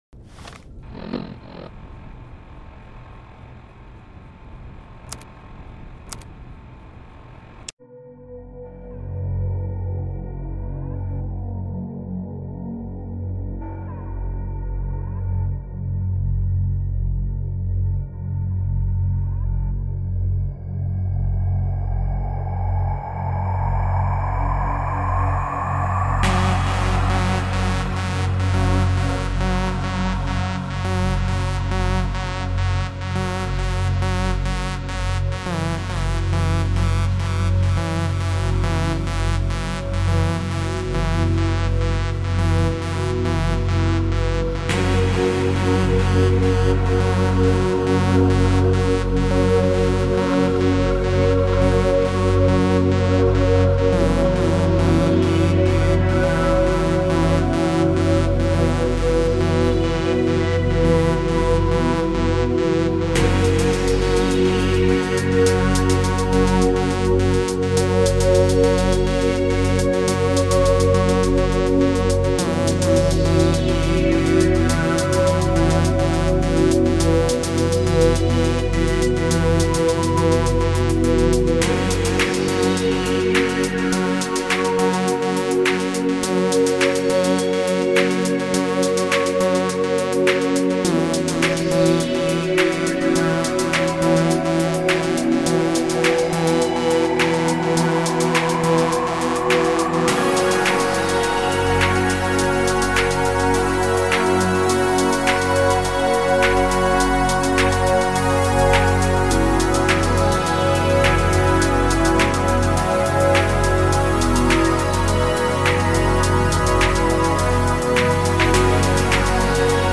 calming, electronic vibes